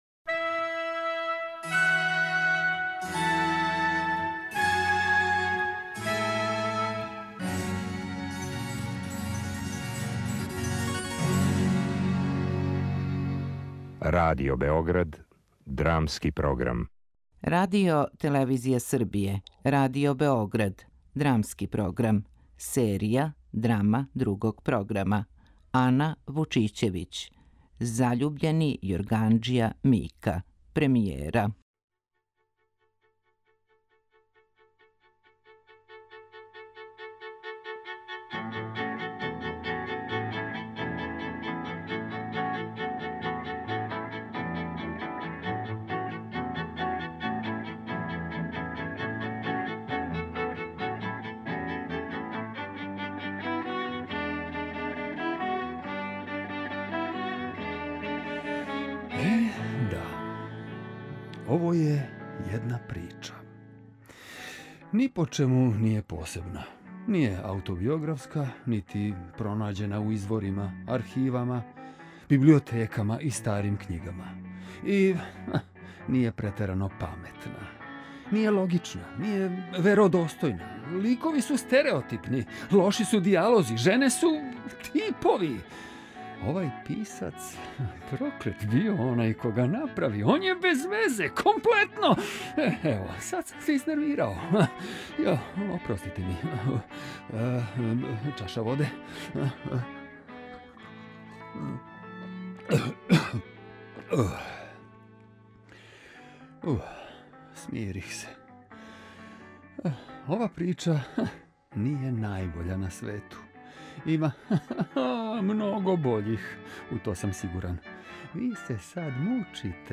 Драма Другог програма
drama_mika.mp3